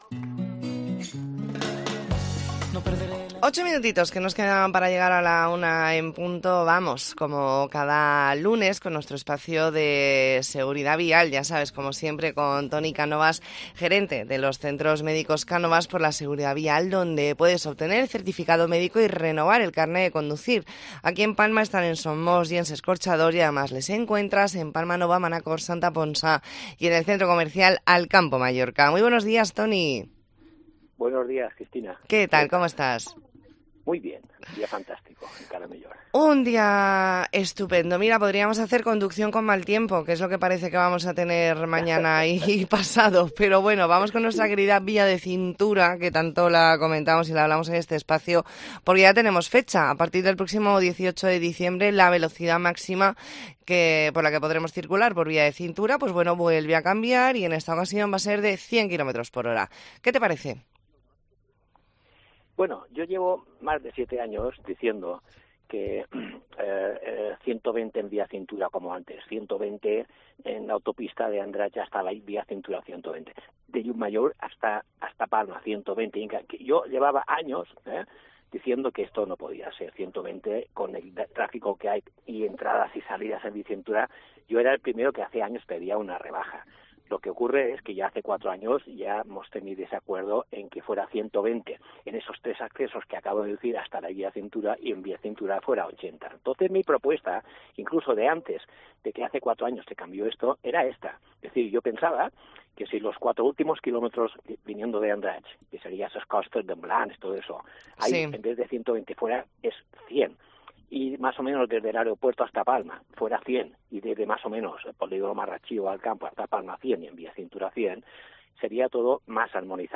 Entrevista en La Mañana en COPE Más Mallorca, lunes 20 de noviembre de 2023.